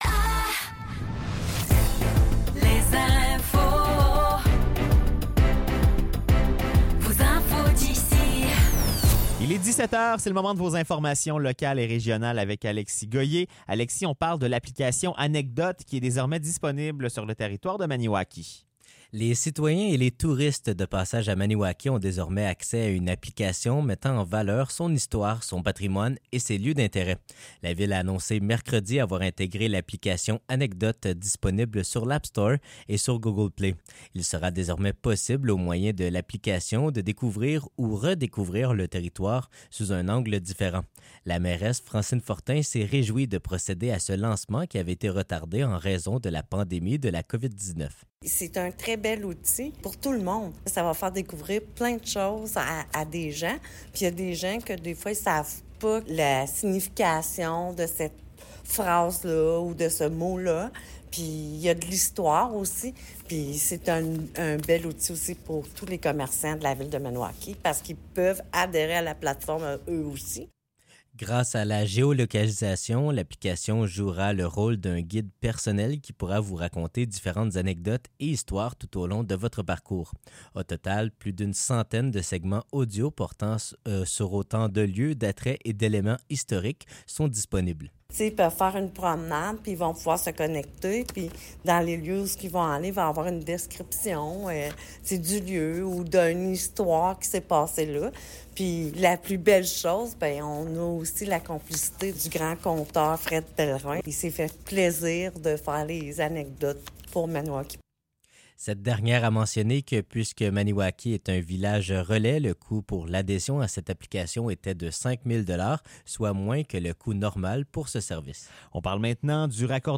Nouvelles locales - 16 mai 2024 - 17 h